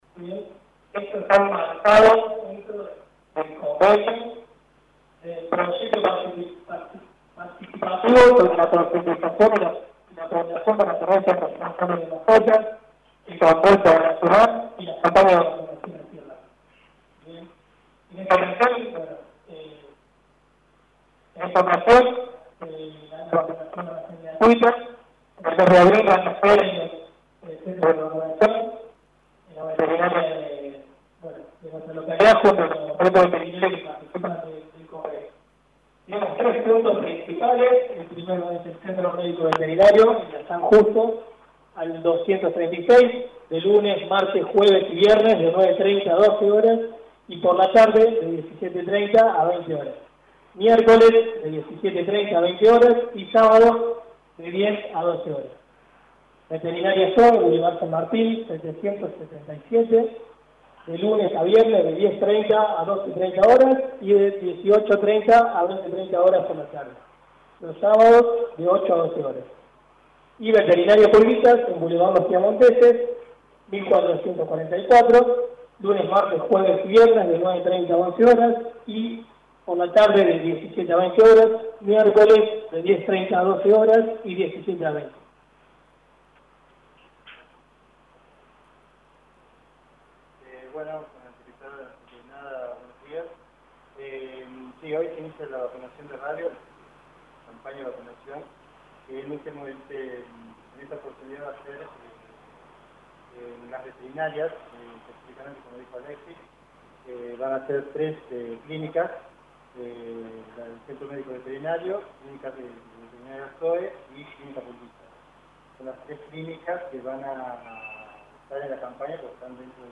En conferencia de prensa, se presentó la nueva Campaña de Vacunación Antirrábica para perros y gatos que se realizará durante todo el mes de abril en el marco del «Mes del Animal».